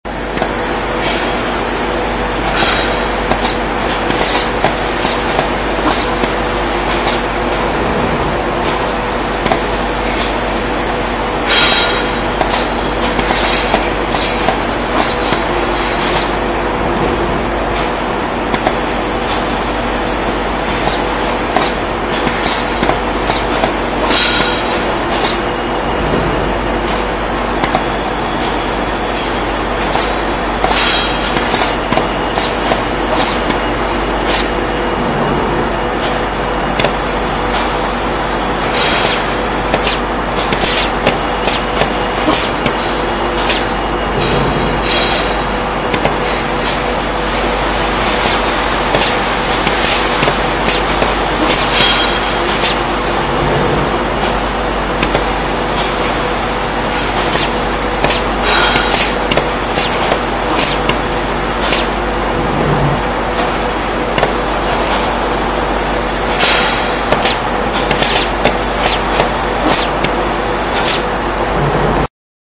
螺线管1
描述：电磁阀声音，机器人画。
Tag: 电磁铁 机械 机械 机器 工厂 机器人 工业